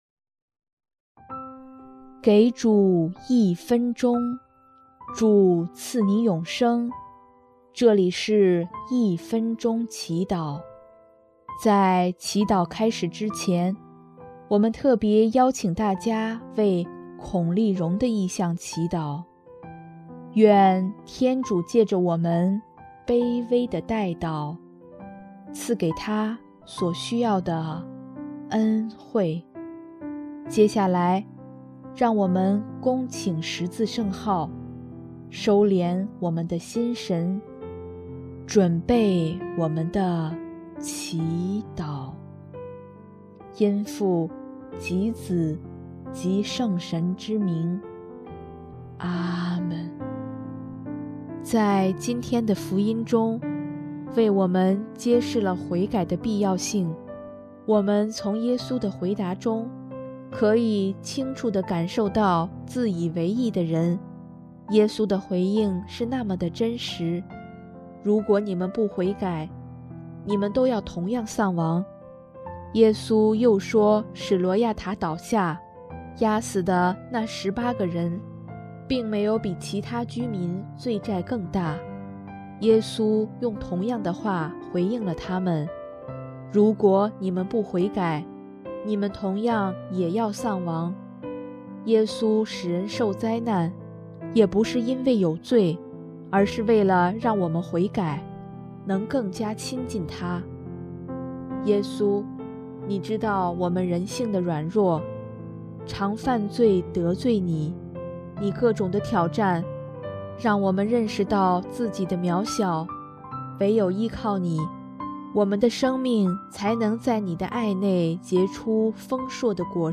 音乐：第四届华语圣歌大赛参赛歌曲《圣保禄赞》